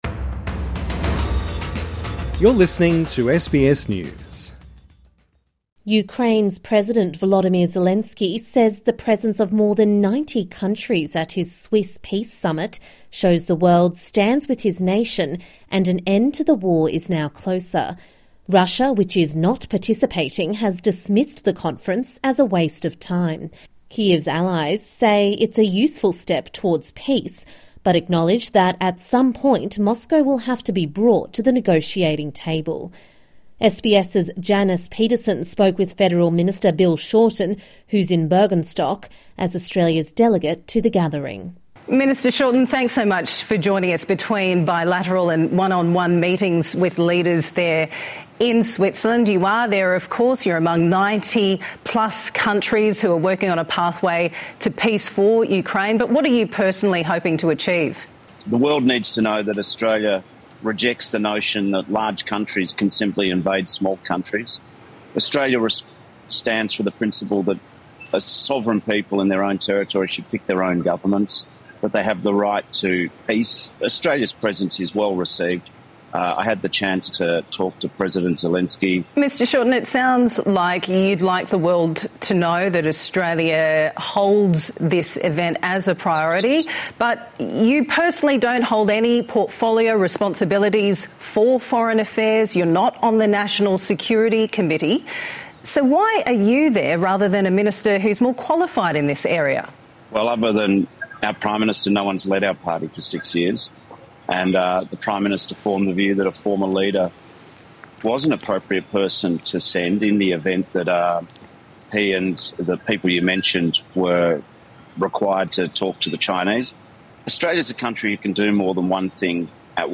INTERVIEW: Federal Minister Bill Shorten speaks to SBS from Ukraine peace summit in Switzerland